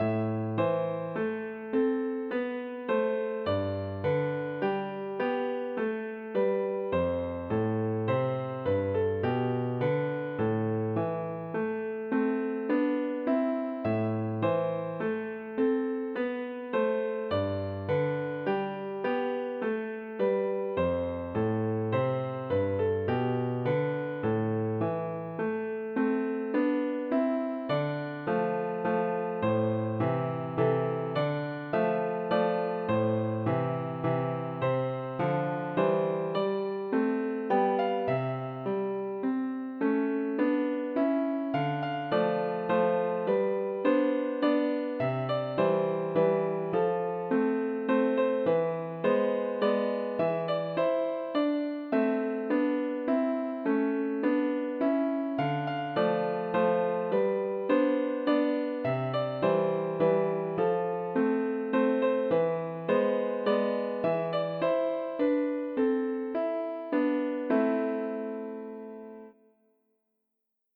Based on the Full Notation sheet music